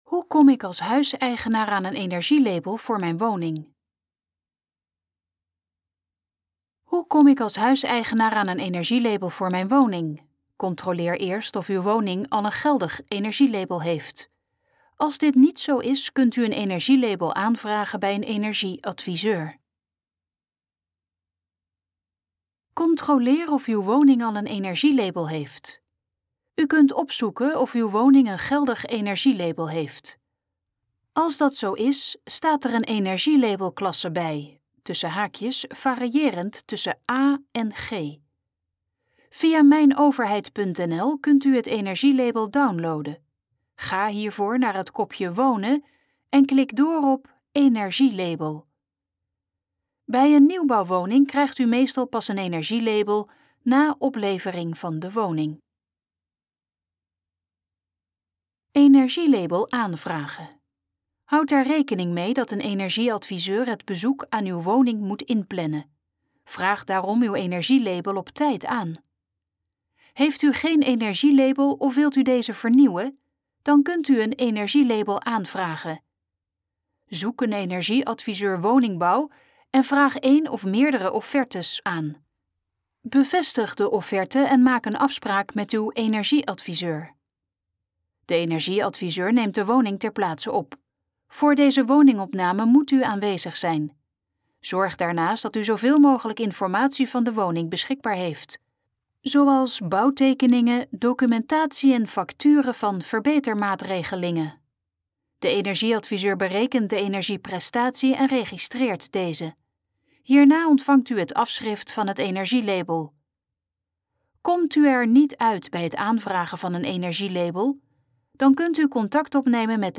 Dit geluidsfragment is de gesproken versie van de pagina Hoe kom ik als huiseigenaar aan een energielabel voor mijn woning?